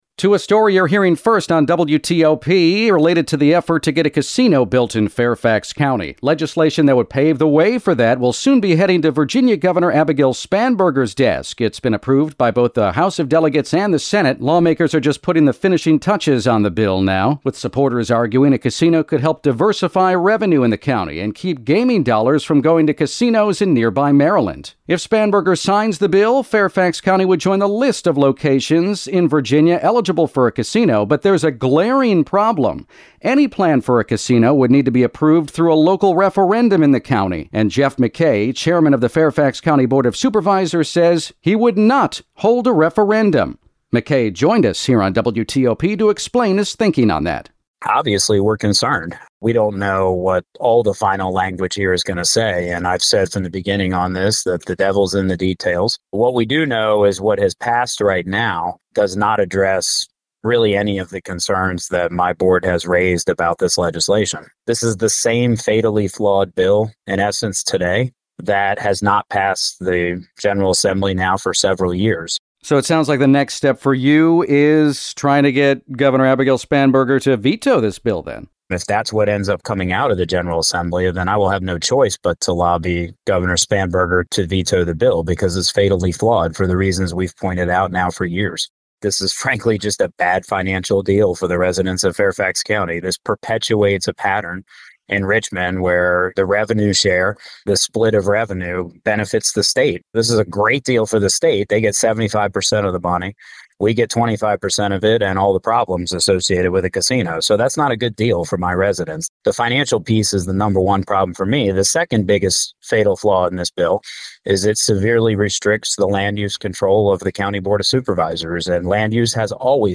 hears from the chairman about why he's against putting the casino issue to a vote.